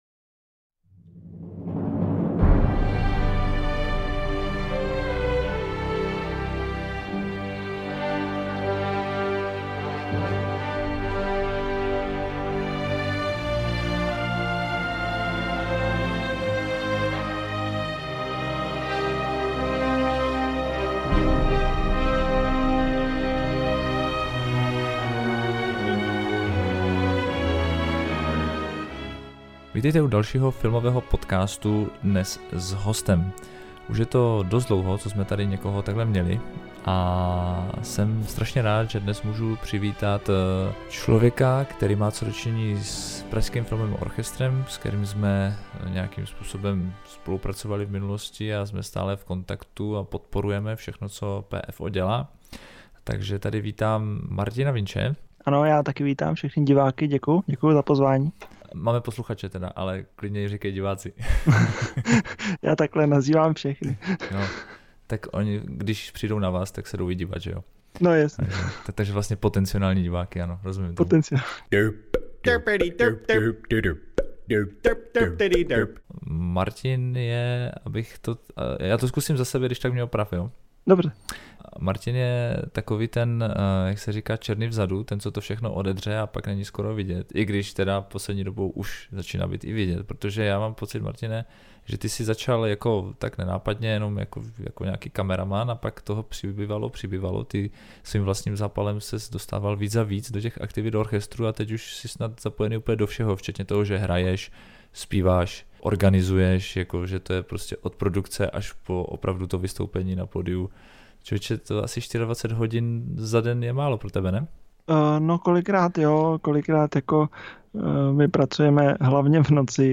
Tradiční kvíz pro hosta na konci rozhovoru však nechybí.